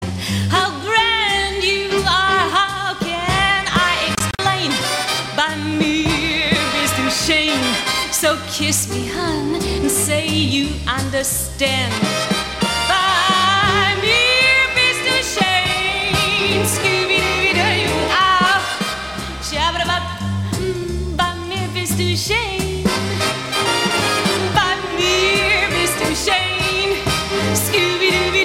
Besetzung: Blasorchester
Tonart: Es-Dur/F-Dur